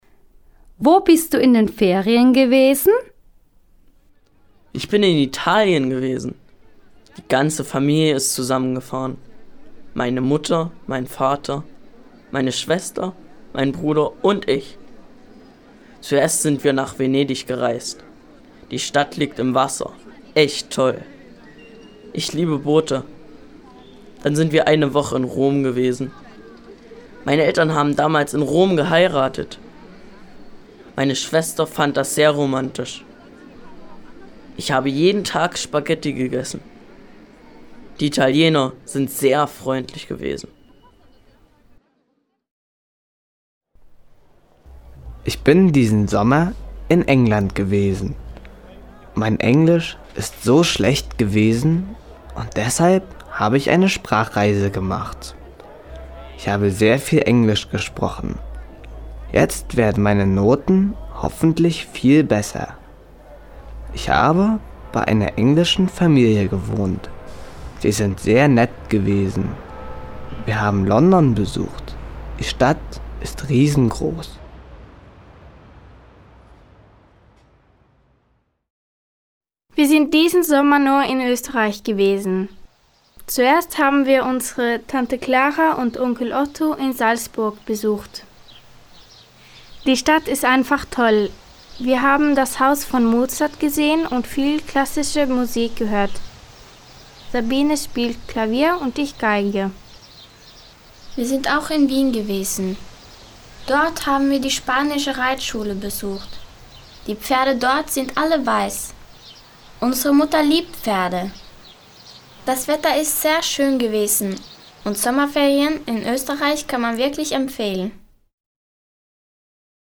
Lytteprøve